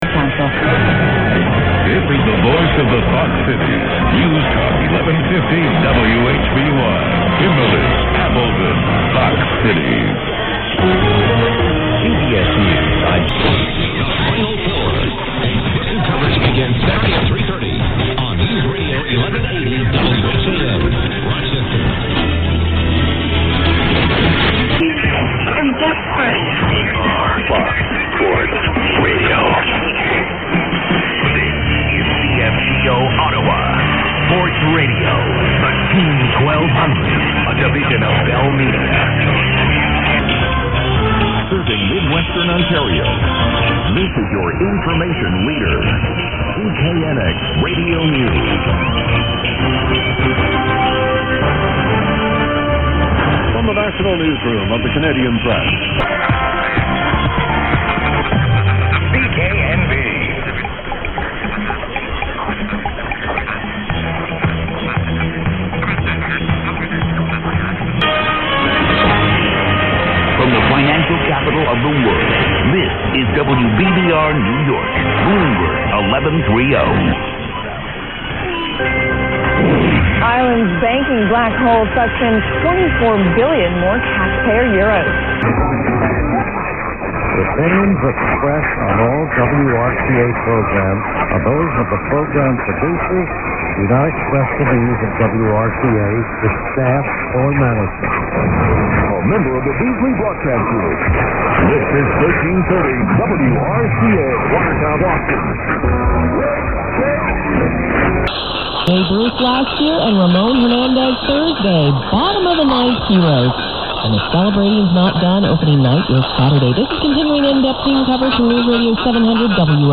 MONTAGE FROM THIS MORNING:
I include a montage of common stations from around 05 and 06 today, maybe the odd one from 0400.